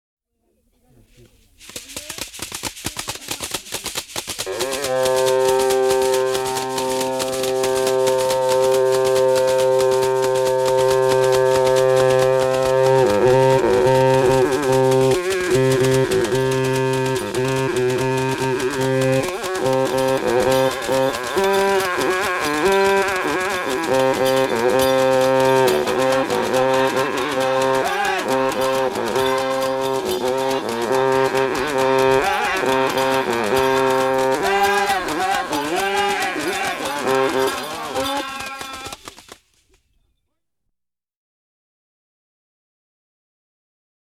Alto Bung'o Horn